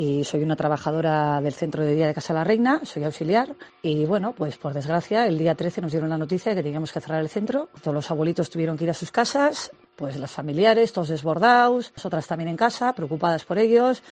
Con el sonido de campanas de fondo